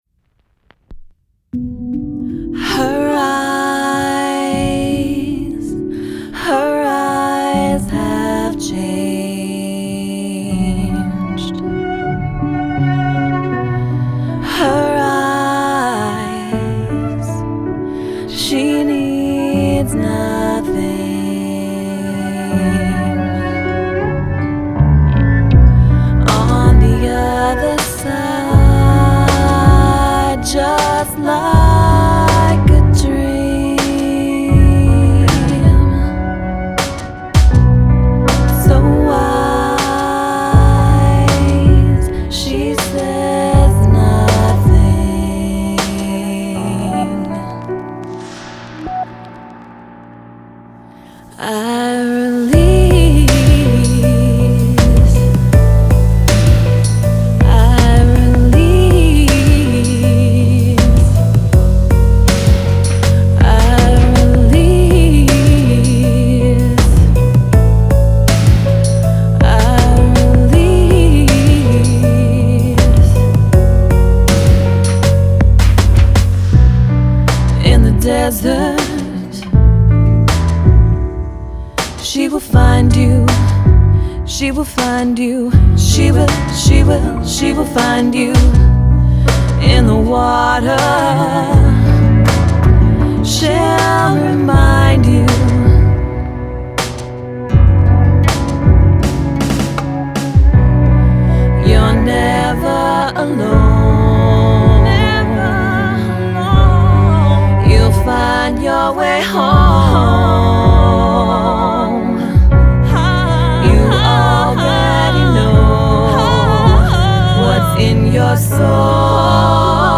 vocals, piano
cello
guitar
bass
beats